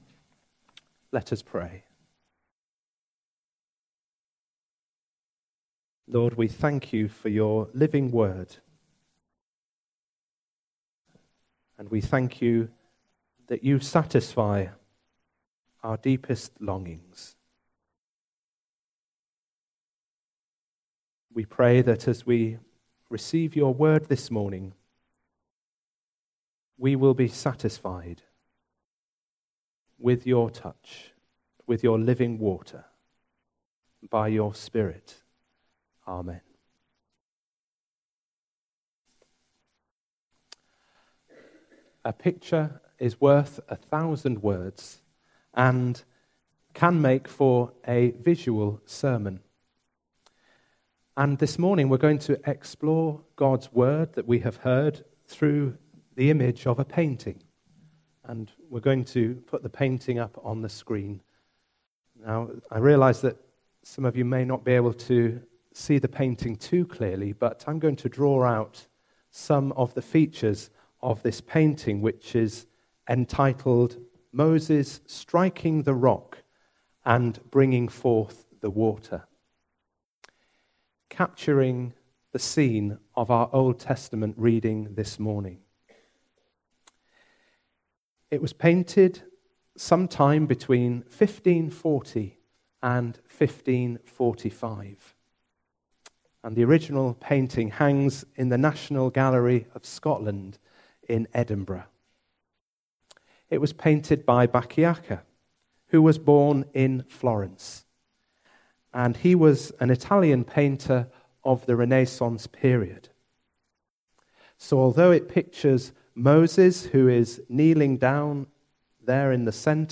Service Type: Sunday Morning
03-19am-sermon.mp3